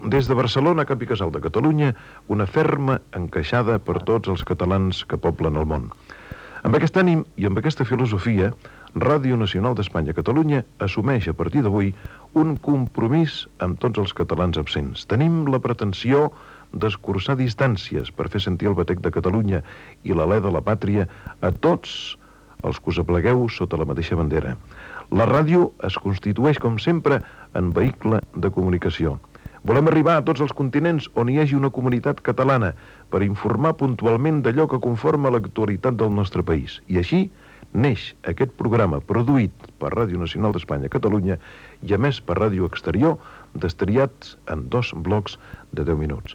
Paraules de presentació el dia de l'emissió inaugural